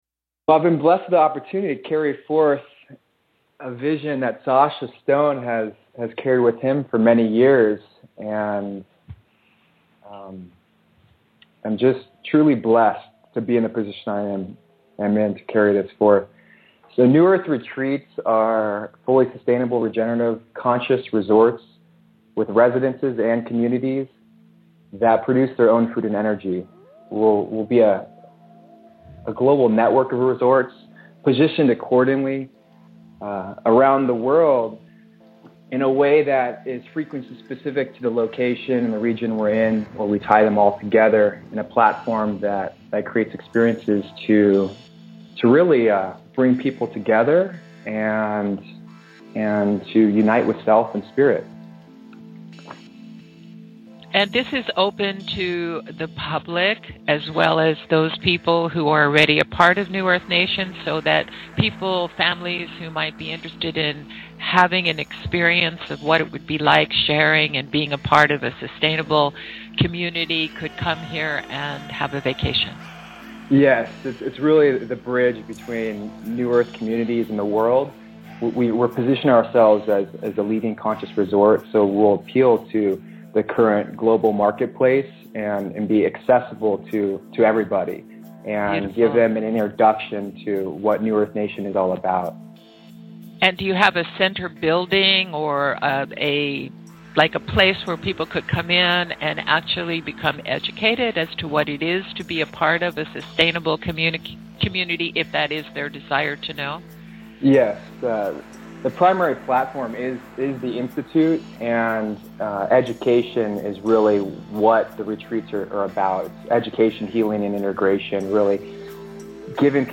These are the salient points from a 2-hour radio interview explaining the overall vision of the New Earth Retreats.